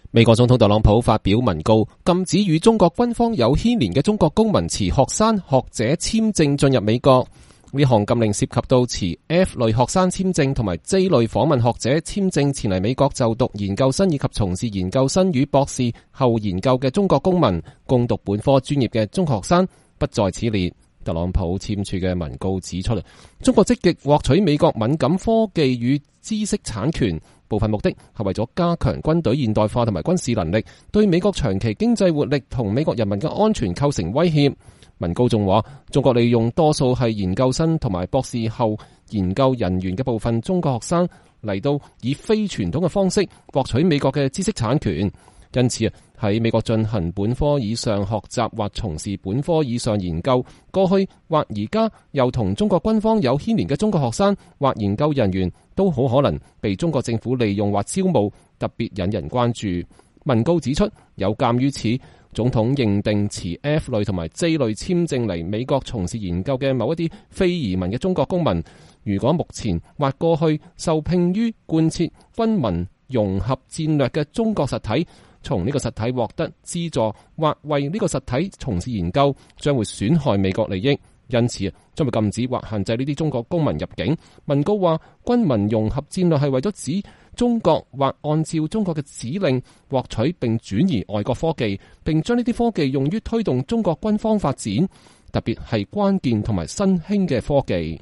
美國總統特朗普5月29日在白宮玫瑰園就中國問題對記者發表講話。